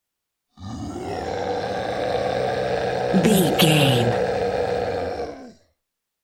Monster scream big creature
Sound Effects
scary
ominous
eerie
horror